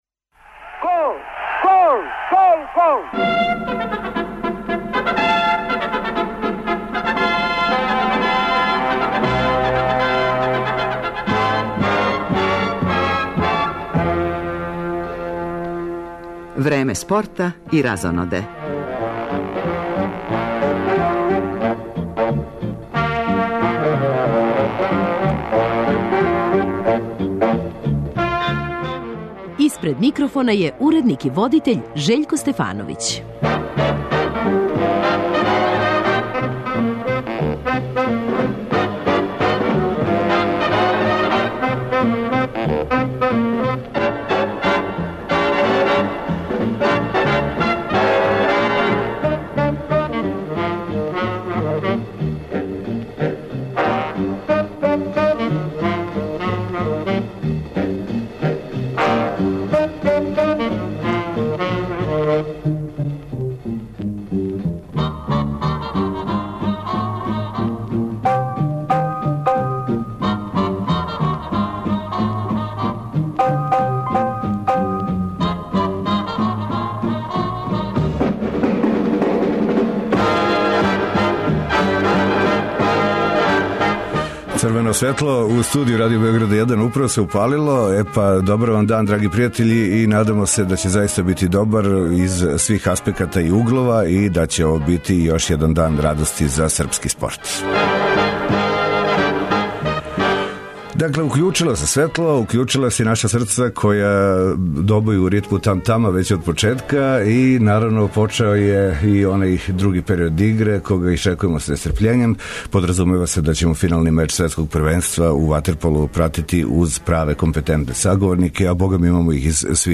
Уз стручне консултанте, пратимо ток резултата и дешавања у мечу који би нашем спорту поново могао донети много радости.